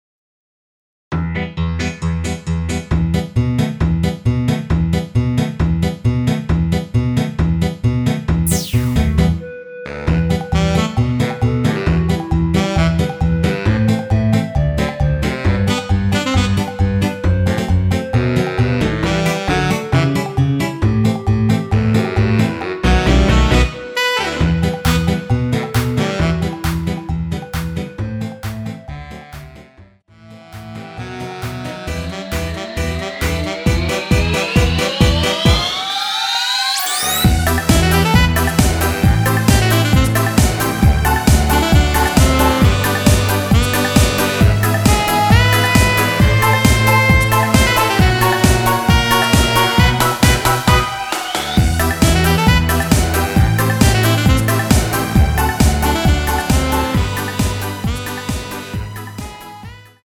(-2)내린 멜로디 포함된 MR이며 멜로디 음색을 앞부분과 뒷부분을 다르게 제작하였습니다.(미리듣기 참조)
Em
앞부분30초, 뒷부분30초씩 편집해서 올려 드리고 있습니다.
중간에 음이 끈어지고 다시 나오는 이유는